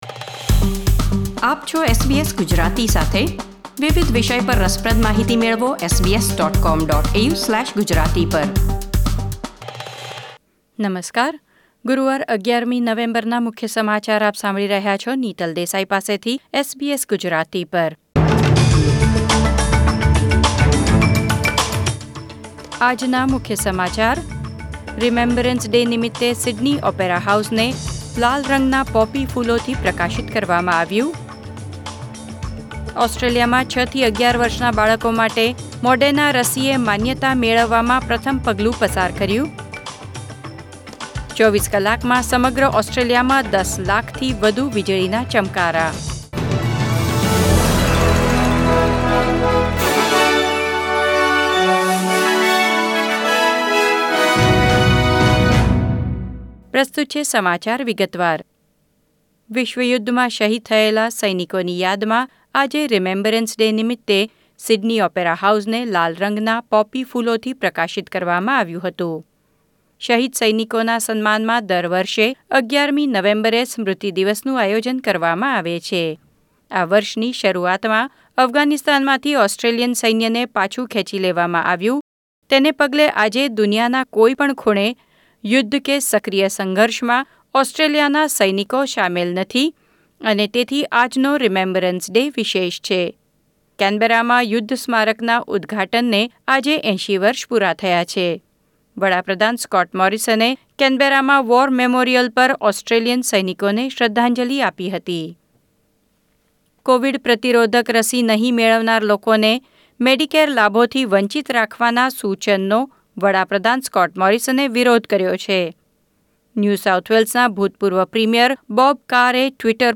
SBS Gujarati News Bulletin 11 November 2021